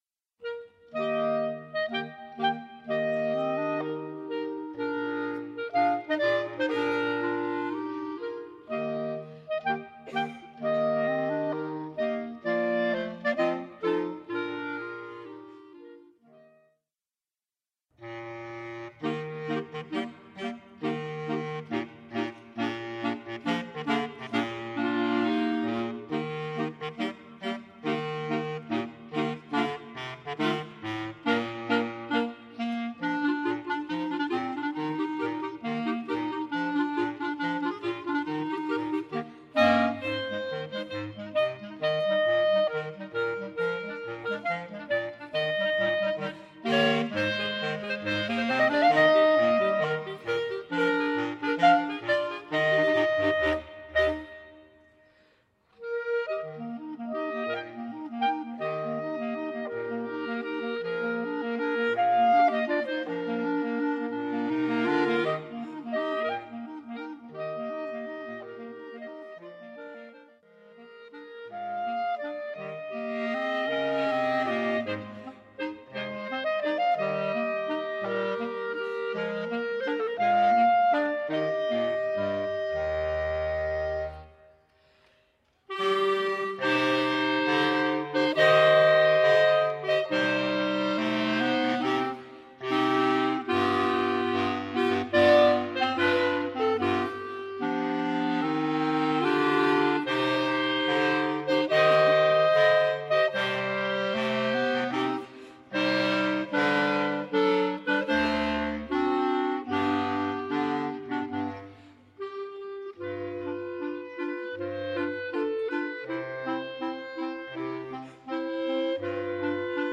Klarinettenquartett (3 B-Klarinetten und Bassklarinette)
Marsch, Walzer, Trauermarsch, Boogie